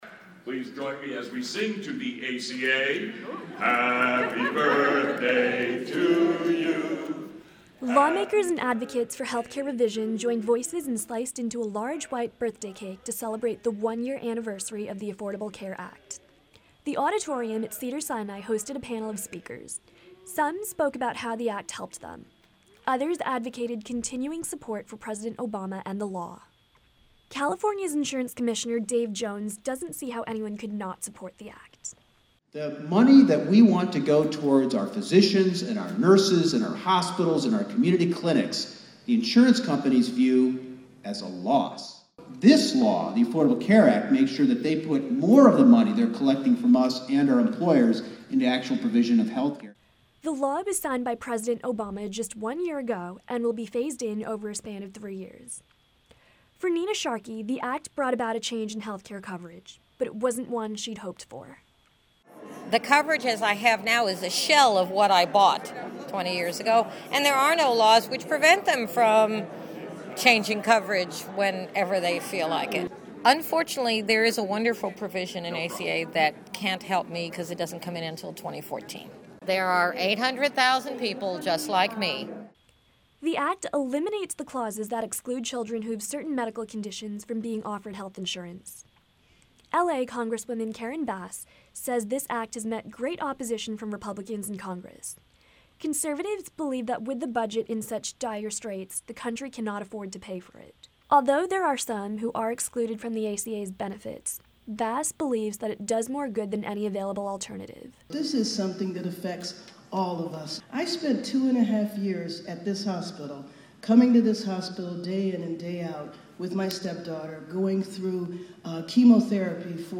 Lawmakers and advocates for health care revision joined voices and sliced into a large white birthday cake to celebrate the one year anniversary of the Affordable Healthcare Act. The auditorium at Cedar-Sinai hosted a panel of speakers.